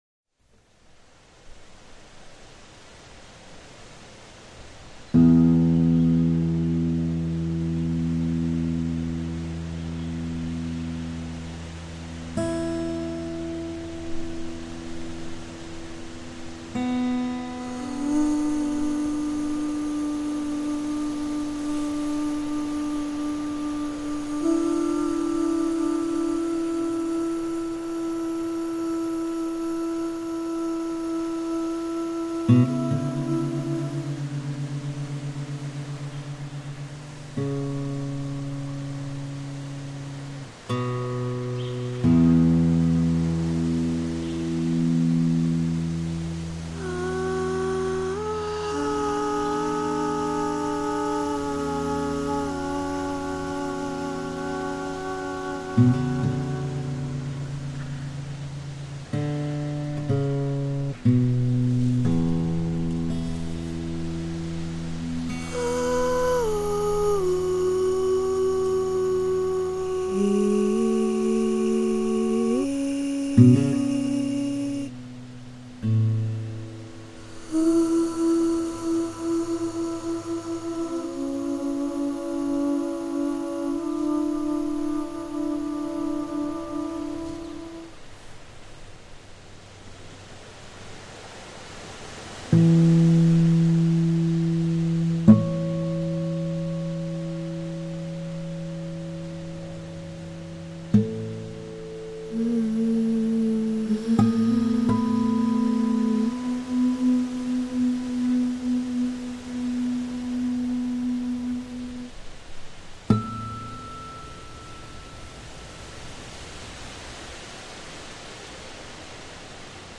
Wind on Suomenlinna, Helsinki reimagined